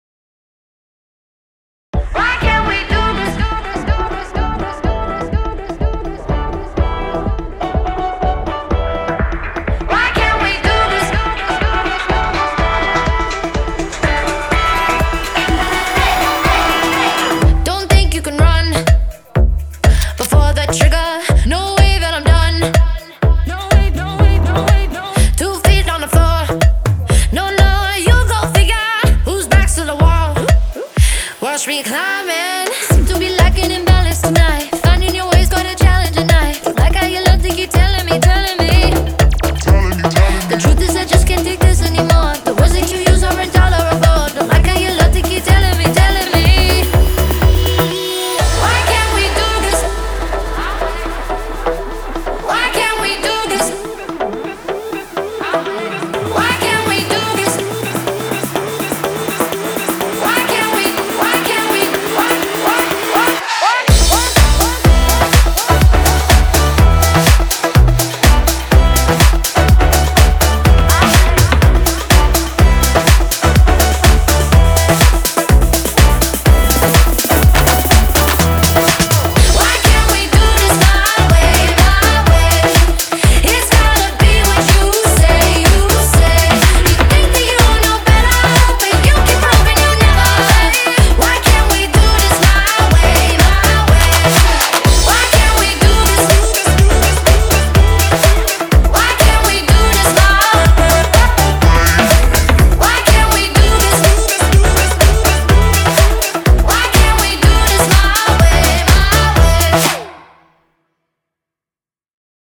BPM124